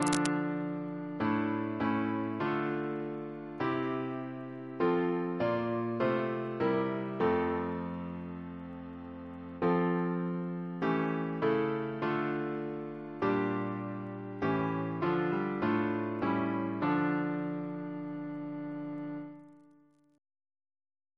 CCP: Chant sampler
Double chant in D minor Composer: Matthew Camidge (1758-1844), Organist of York Minster Reference psalters: ACB: 65; ACP: 19; CWP: 12; H1982: S238; OCB: 143; PP/SNCB: 102; RSCM: 29